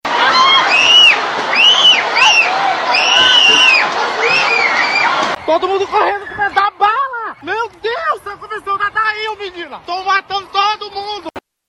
A convenção da chapa de Adail Pinheiro (Republicanos) foi marcada por correria e gritaria após barulhos de fogos serem confundidos com disparos.
É possível ouvir gritos e choro de crianças.(Ouça)